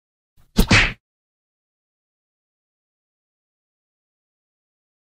دانلود آهنگ مشت زدن 3 از افکت صوتی انسان و موجودات زنده
دانلود صدای مشت زدن 3 از ساعد نیوز با لینک مستقیم و کیفیت بالا
جلوه های صوتی